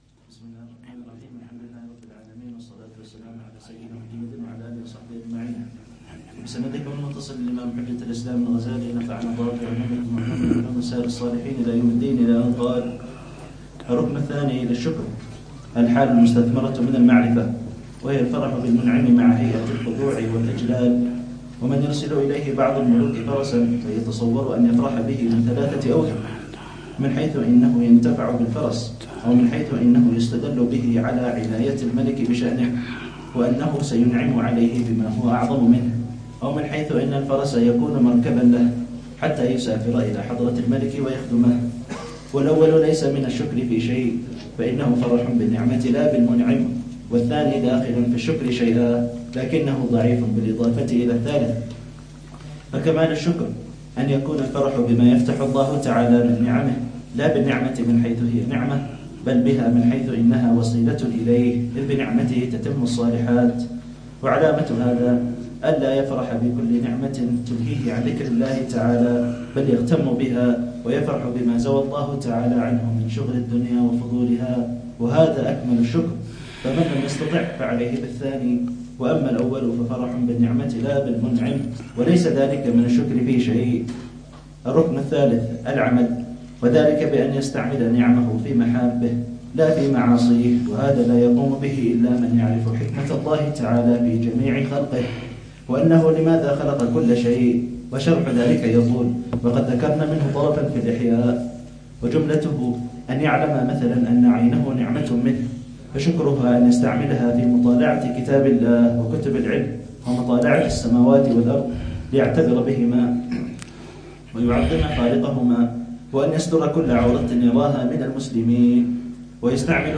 الدرس ال36 في كتاب الأربعين في أصول الدين: الشكر: علم وحال وعمل…